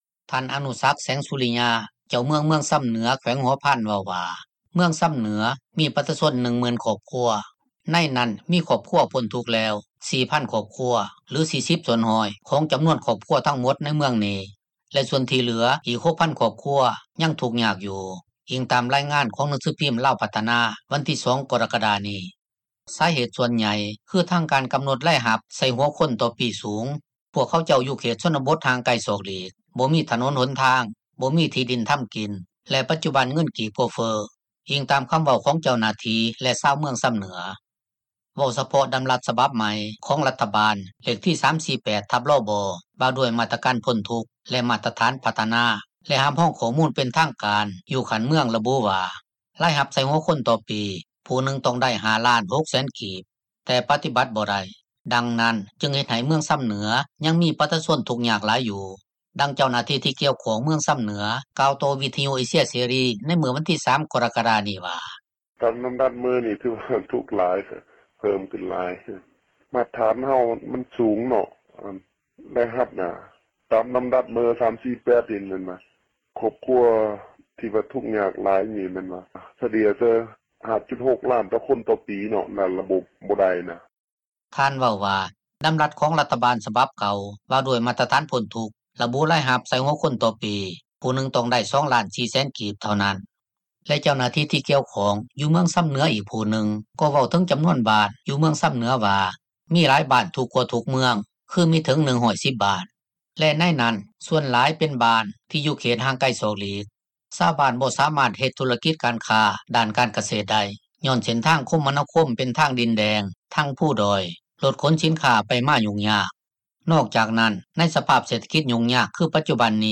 ດັ່ງເຈົ້າໜ້າທີ່ ທີ່ກ່ຽວຂ້ອງເມືອງຊໍາເໜືອ ກ່າວຕໍ່ວິທຍຸ ເອເຊັຽ ເສຣີ ໃນມື້ວັນທີ 3 ກໍຣະກະດານີ້ວ່າ: